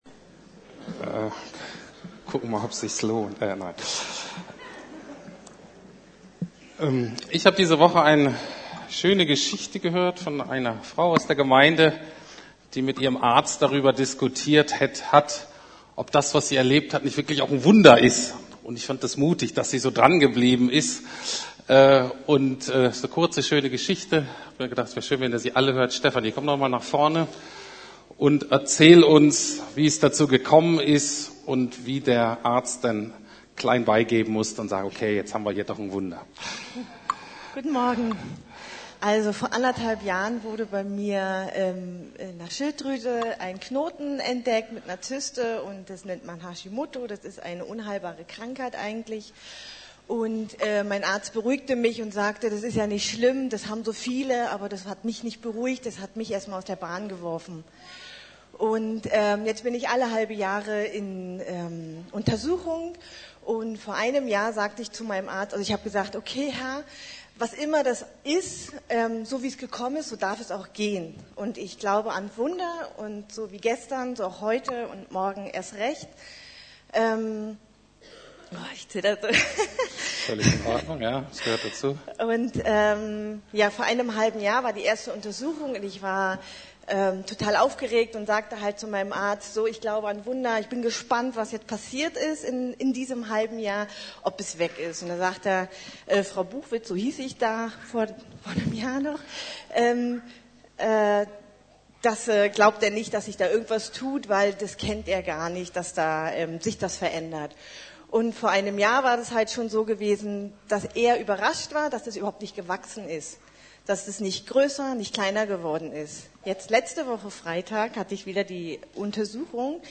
Wie Gott die Welt regiert - DIE FAMILIE (Teil 2) ~ Predigten der LUKAS GEMEINDE Podcast